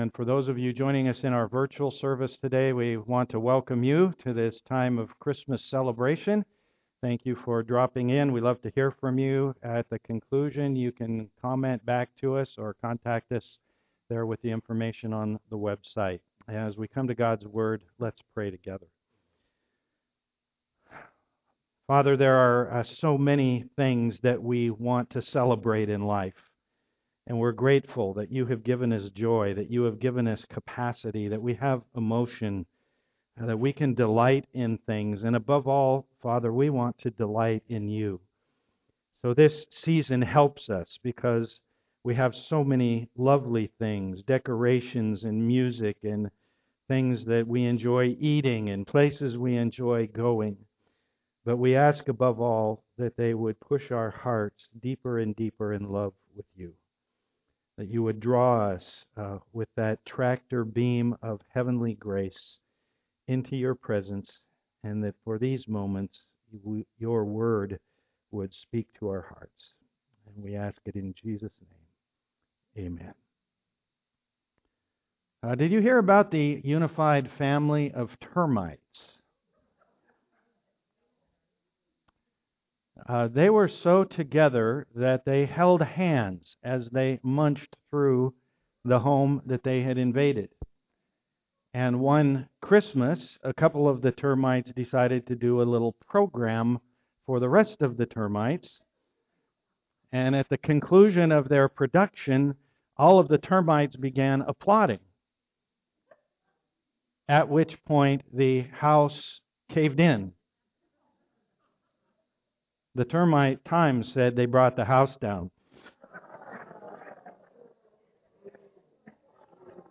Luke 2:16-18 Service Type: am worship Sometimes we stumble into believing things that are not totally biblical.